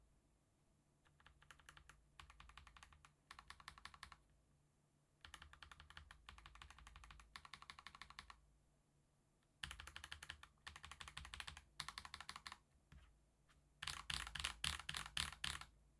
ちょっと安っぽいカチカチした音が鳴るんだけど、押してる実感が得られて、気持ちよくタイピングできる。
音は69〜75dBあたり。タクタイルとしてはまぁ普通。そんなうるさくはない。
同じマイクセッティングで同じように打鍵したデータを用意した。ゲイン調整もしていない。
Jupiter Bananaの打鍵音